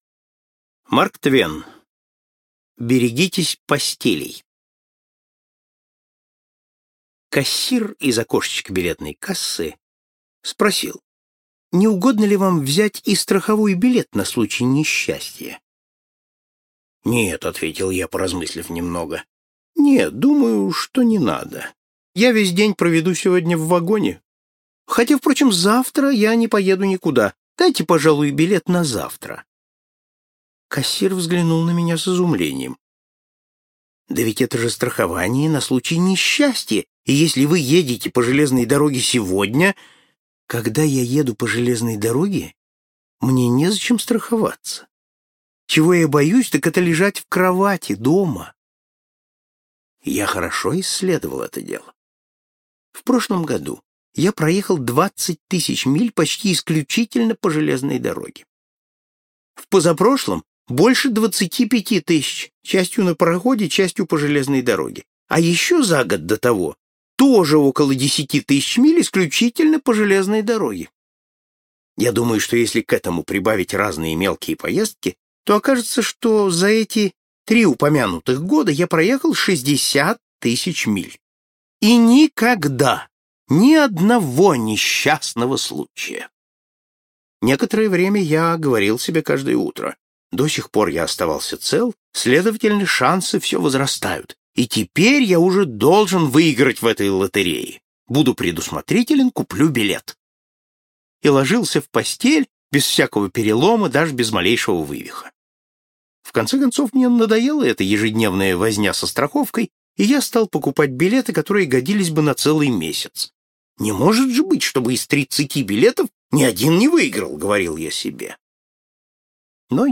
Аудиокнига Моя автобиография. Сборник рассказов | Библиотека аудиокниг
Сборник рассказов Автор Марк Твен Читает аудиокнигу Вениамин Смехов.